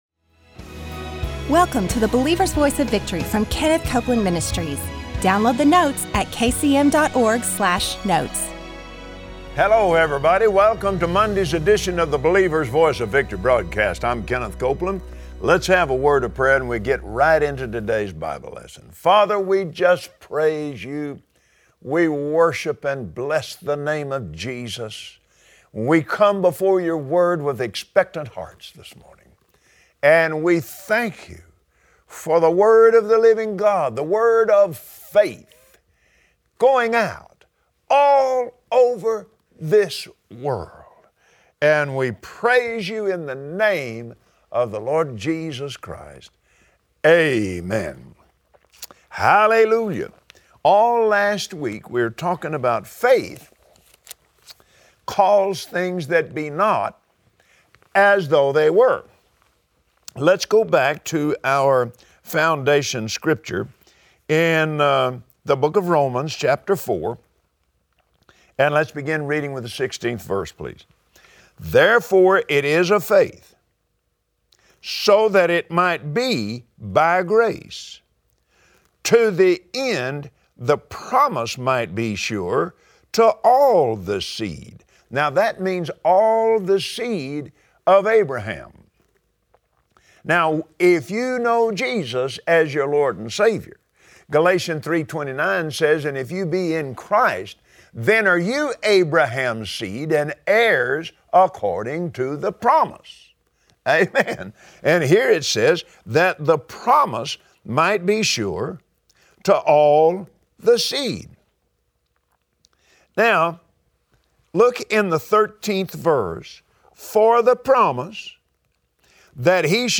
Believers Voice of Victory Audio Broadcast for Monday 08/15/2016 Your covenant with God is filled with life, faith, and victory. Join Kenneth Copeland today as he teaches us that every promise of God is backed by Jesus, and He’s made that overcoming faith power available to you.